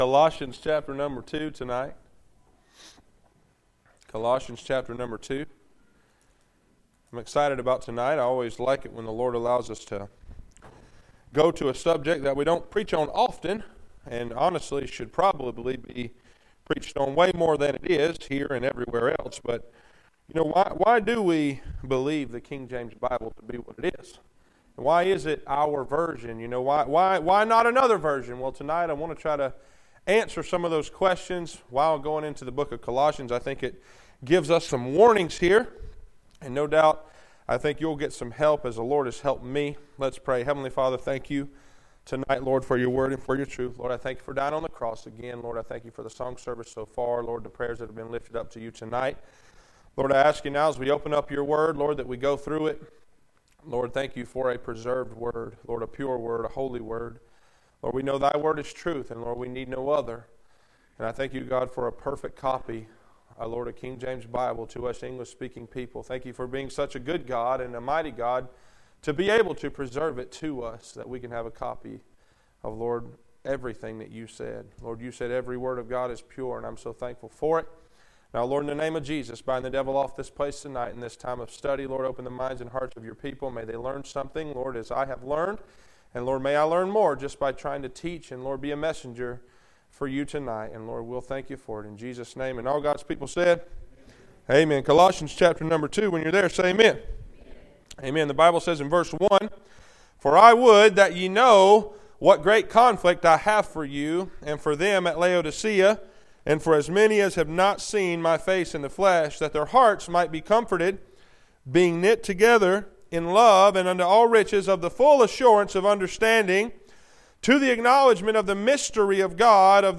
Passage: Col 2:1-8 Service Type: Wednesday Night